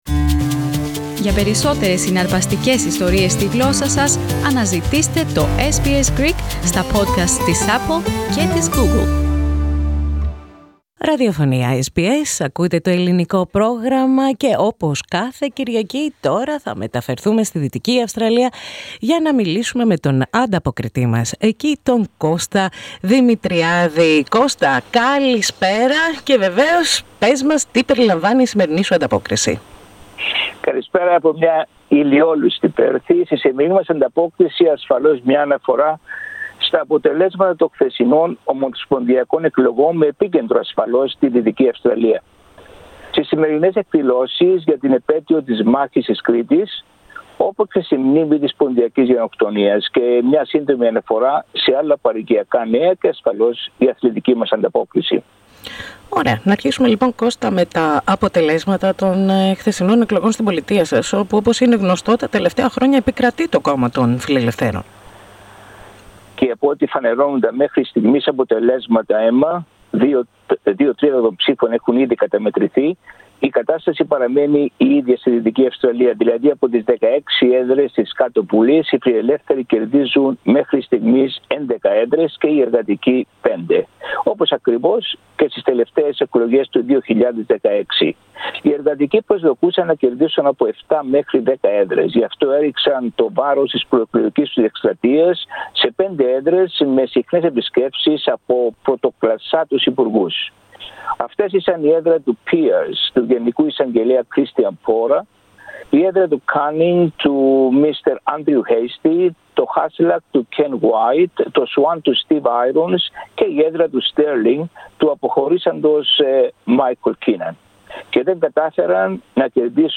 Η ομογένεια στη Δυτική Αυστραλία πραγματοποίησε εκδηλώσεις για την επέτειο της Μάχης της Κρήτης και τη συμπλήρωση 100 χρόνων απο τη Ποντιακή Γενοκτονία. Περισσότερα ομογενειακά και άλλα νέα απο την Δυτική Αυστραλία στην ανταπόκριση μας από την Πέρθη.